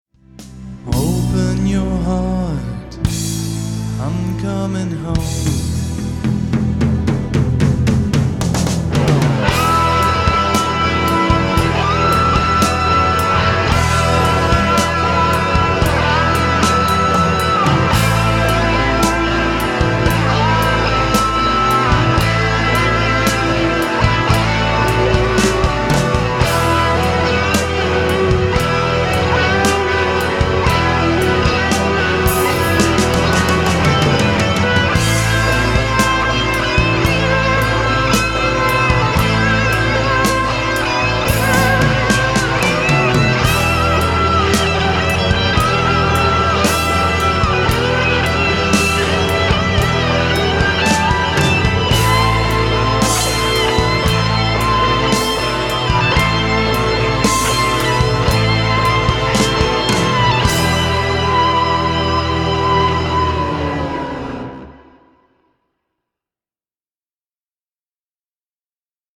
I recorded 3 guitar lines;